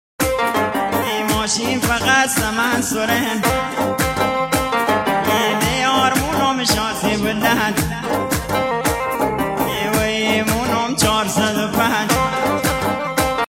fazaye shad va energibakhshi misazad